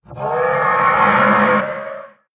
骷髅马在死亡时播放此音效
Minecraft_skeleton_horse_death.mp3